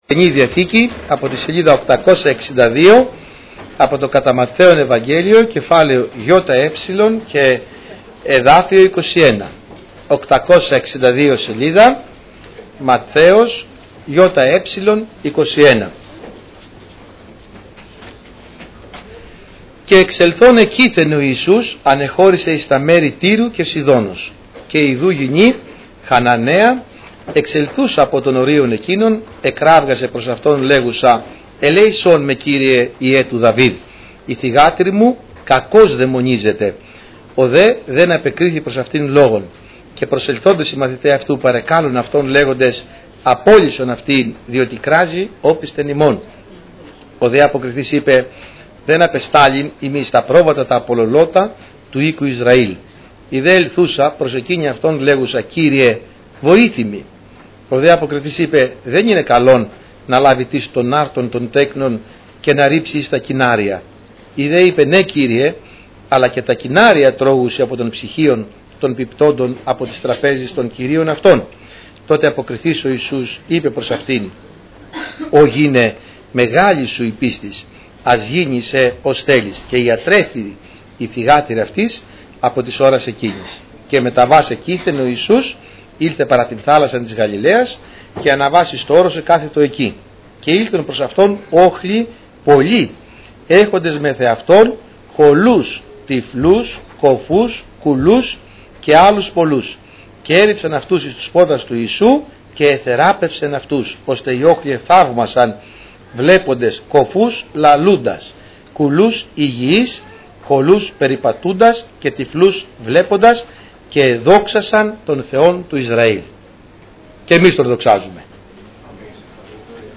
Κυριακάτικα Ημερομηνία